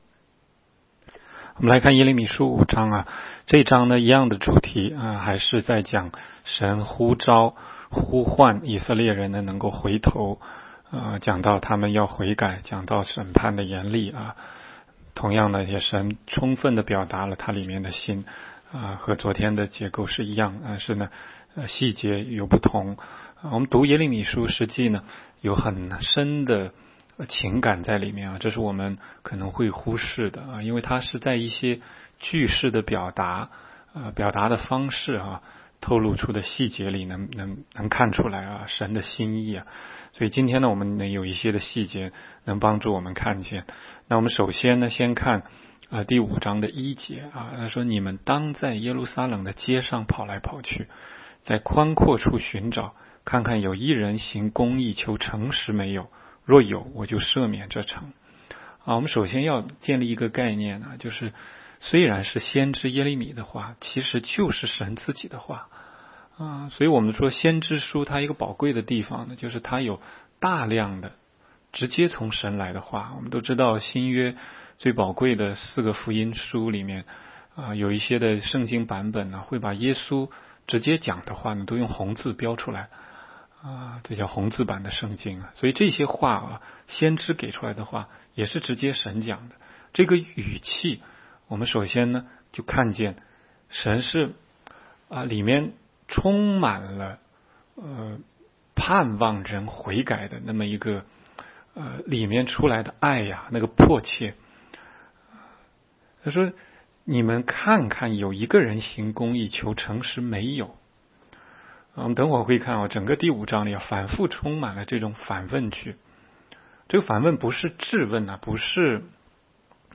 16街讲道录音 - 每日读经 -《耶利米书》5章